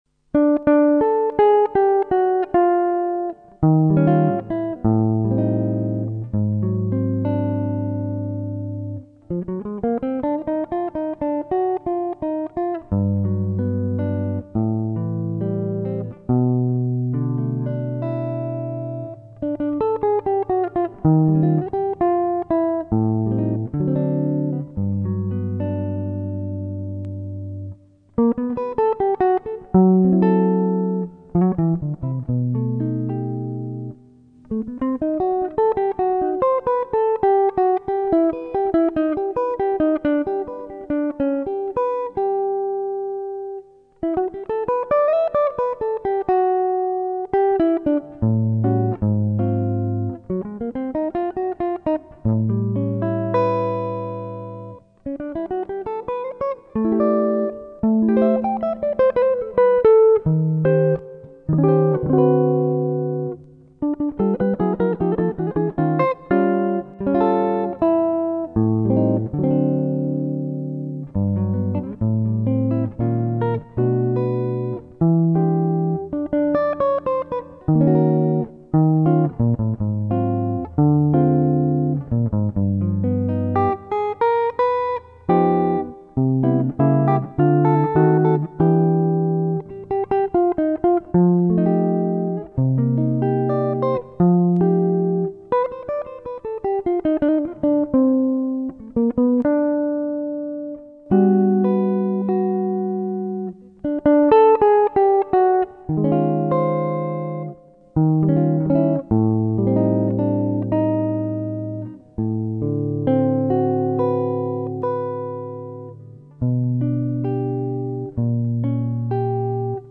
(Solo)   Ascolta brano